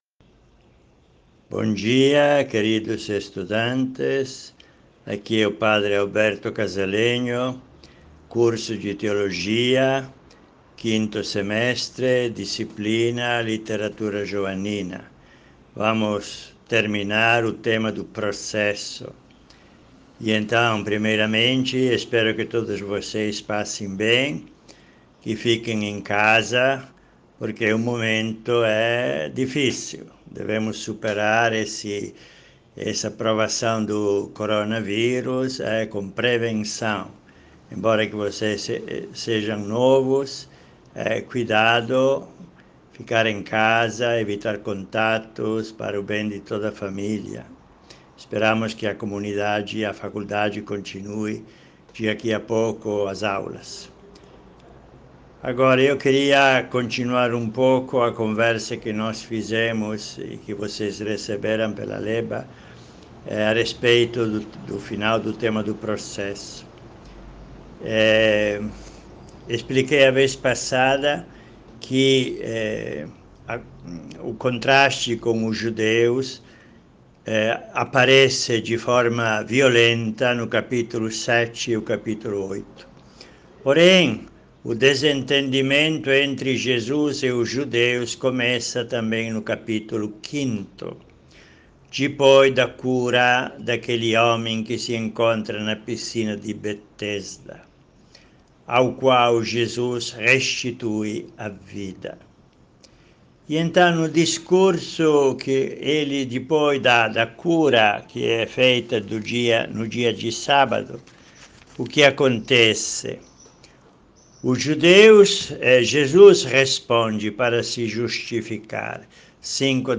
Aula para o 5º semestre de Teologia – Tema: processo